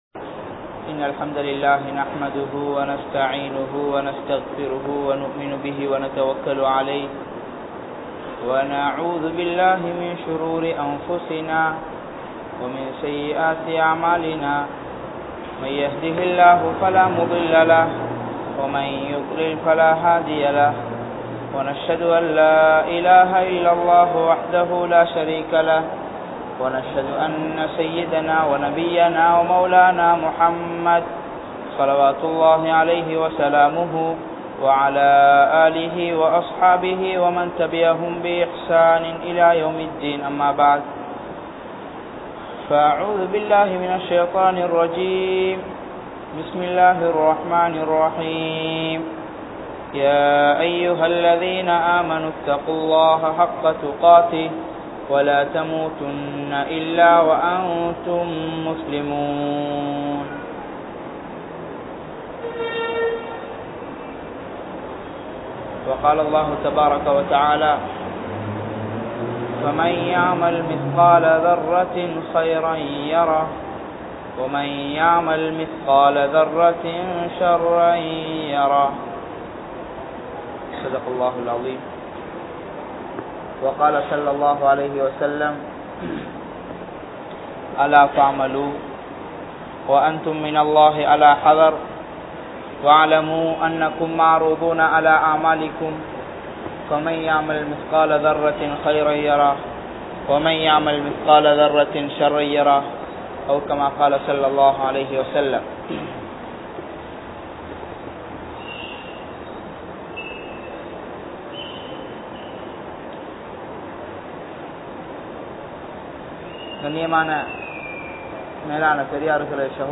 Nantri Ketta Manitharhal (நன்றி கெட்ட மனிதர்கள்) | Audio Bayans | All Ceylon Muslim Youth Community | Addalaichenai